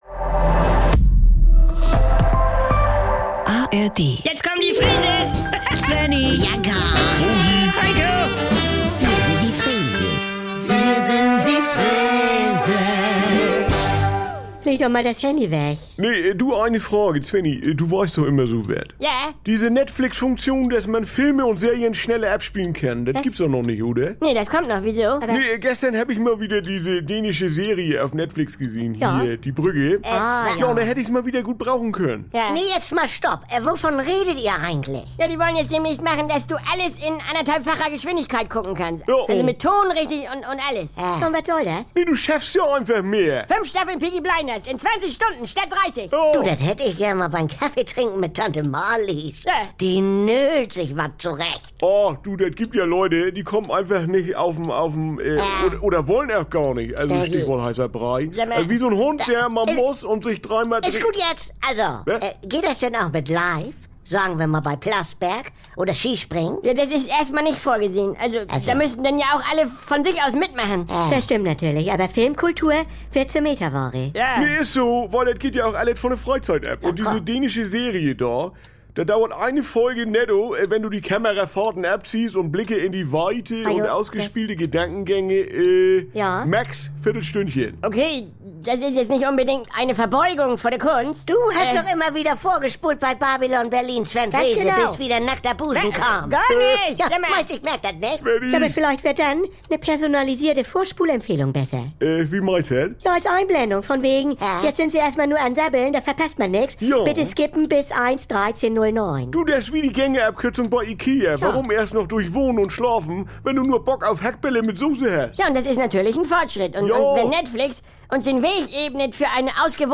Saubere Komödien NDR 2 Komödie Unterhaltung NDR Freeses Comedy